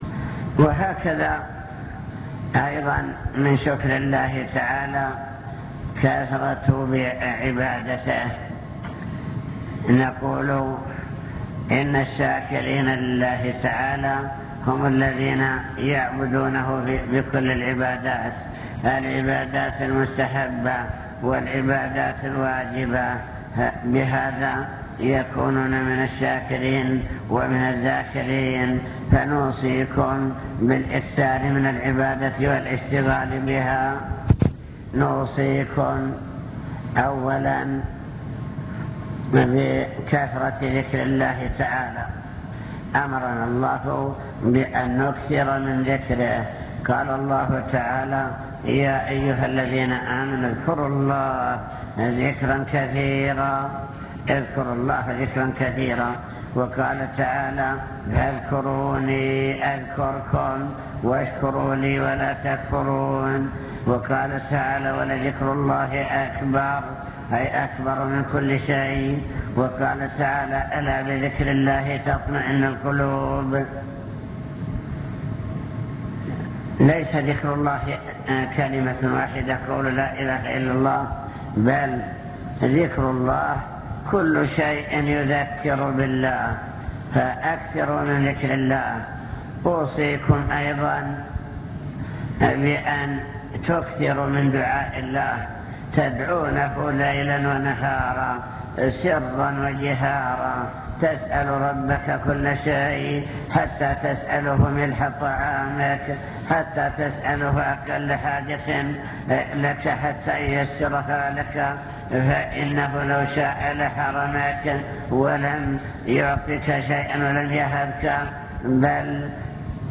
المكتبة الصوتية  تسجيلات - محاضرات ودروس  محاضرة بعنوان شكر النعم (2) واجب الإنسان نحو النعم